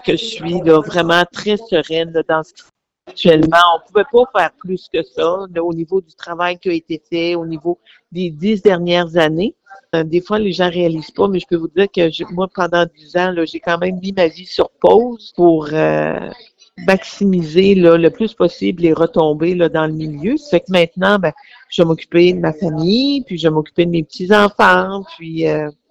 Hier soir, dans son adresse aux journalistes lors du rassemblement de son équipe au cœur de Chandler, Diane Lebouthillier a félicité Alexis Deschênes avant de lui souhaiter un bon mandat.